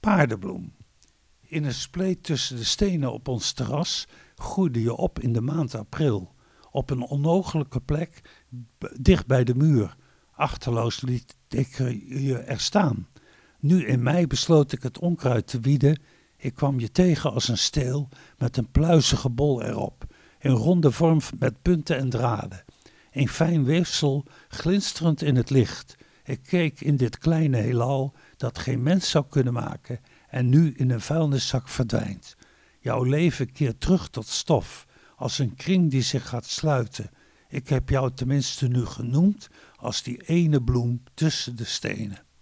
Kletskoek wordt iedere vrijdagochtend live� tussen 10 en 13 uur vanuit de studio�van Radio Capelle uitgezonden.